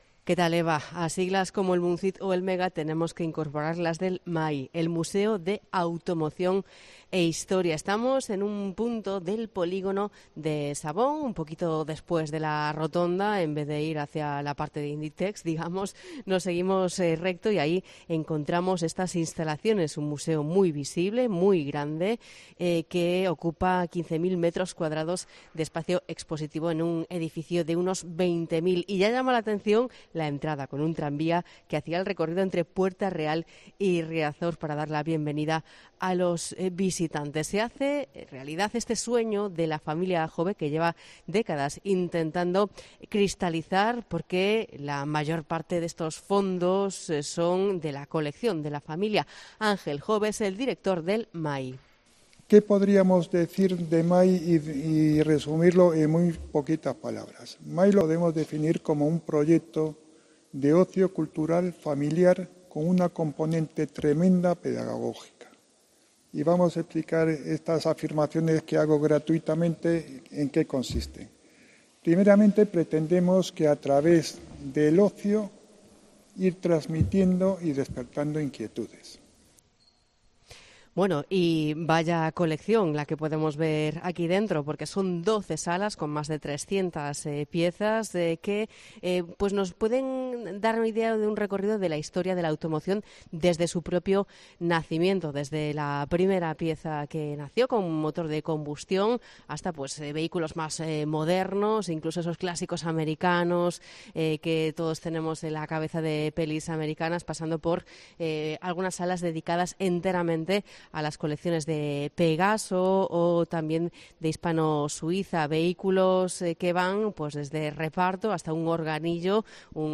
Crónica desde el Museo de Automoción de Arteixo